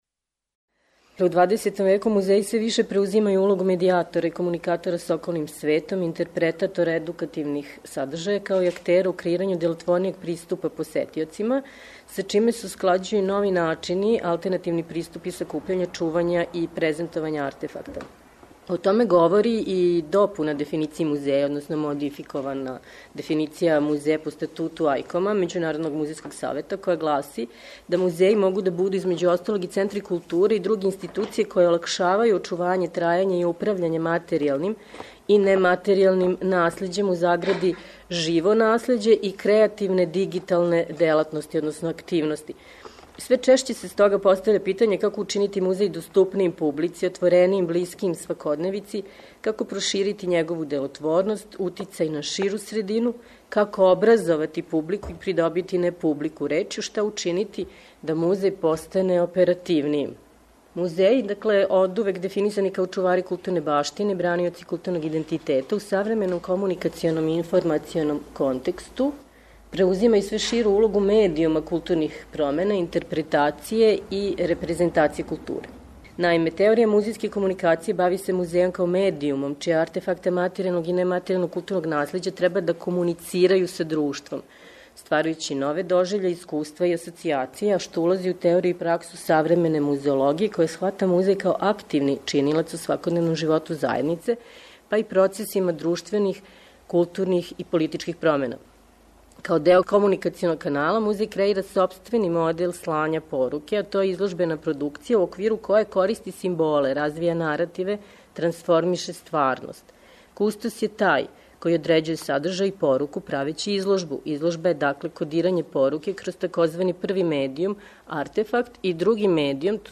преузми : 6.14 MB Трибине и Научни скупови Autor: Редакција Преносимо излагања са научних конференција и трибина.